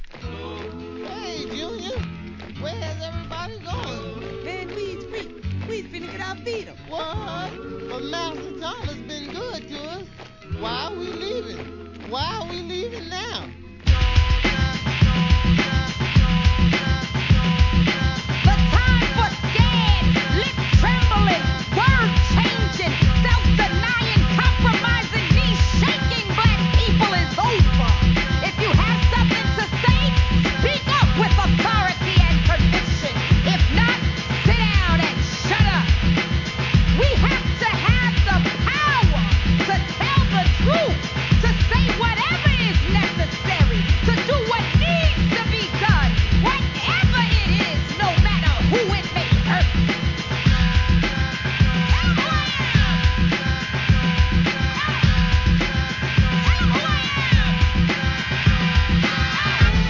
HIP HOP/R&B
コンシャス・フィメールRAP!!!